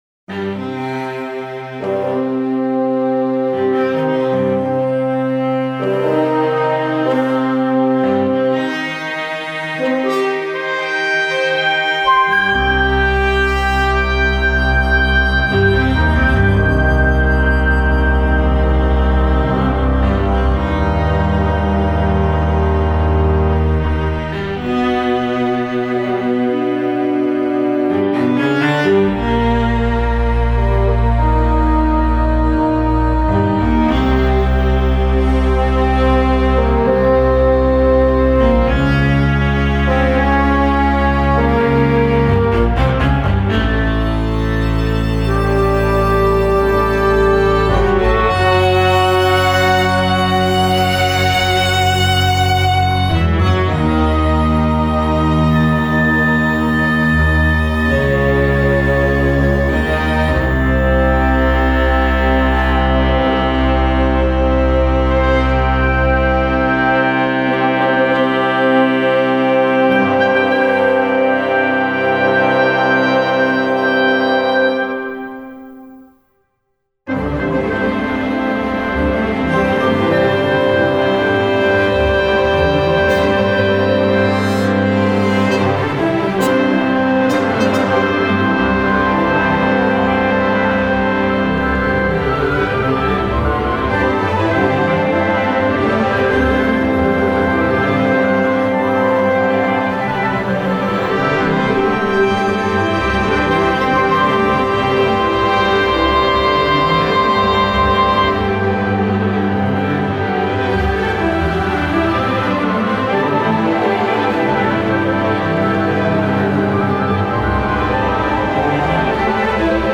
Chamber orchestra (Flute, Oboe, Clarinet, Bassoon, Trumpet, Horn, Trombone, Tuba, 2 violins, Viola, Cello, Double bass).